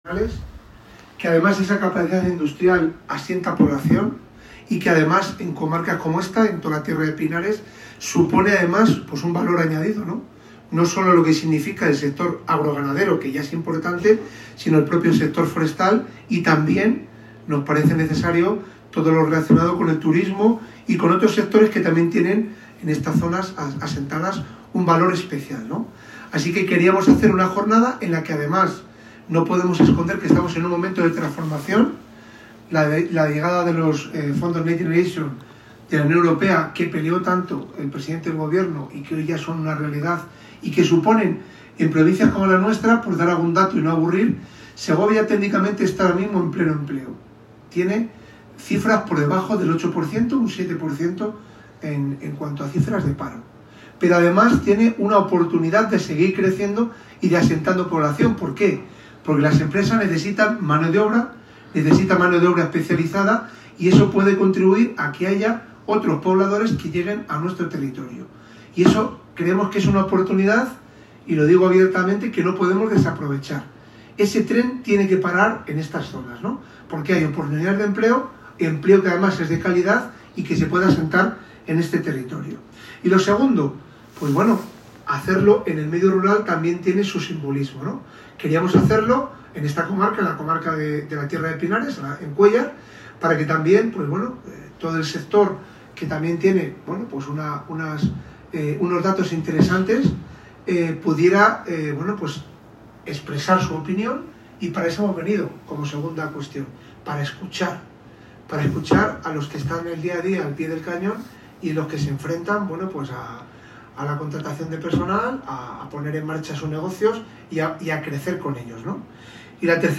La jornada fue inaugurada por el secretario de Estado de Telecomunicaciones e Infraestructuras Digitales, Antonio Hernando, el secretario general del PSOE de Segovia y diputado en el Congreso, José Luis Aceves, y el alcalde de Cuéllar, Carlos Fraile, quienes coincidieron en subrayar que la industrialización, acompañada de la digitalización, debe convertirse en una palanca imprescindible para generar empleo, fijar población y garantizar un futuro de esperanza para las comarcas segovianas.